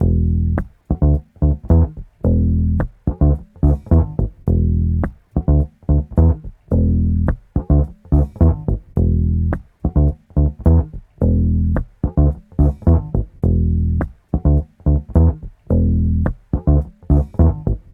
DF_107_E_FUNK_BASS_01.wav